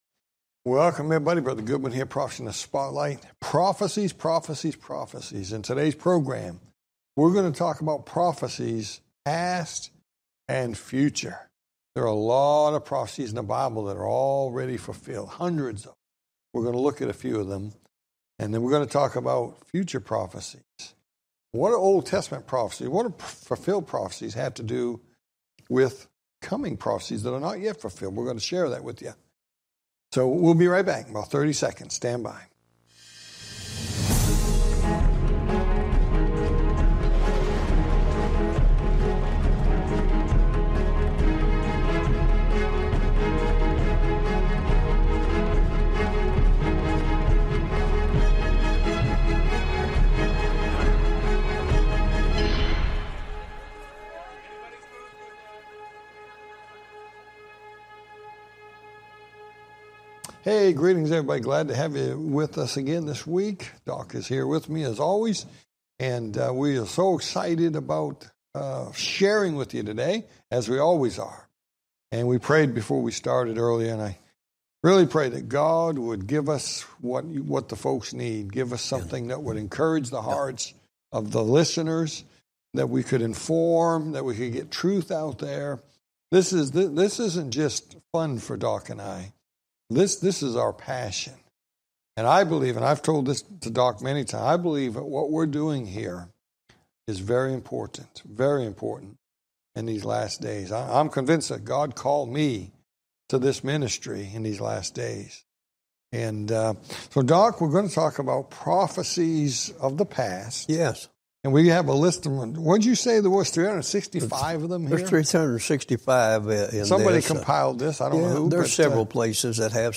Talk Show Episode, Audio Podcast, Prophecy In The Spotlight and Prophecy Past And Future and Walk Through Time on , show guests , about Prophecys Past And Future,Walk Through Time, categorized as History,News,Politics & Government,Religion,Society and Culture,Theory & Conspiracy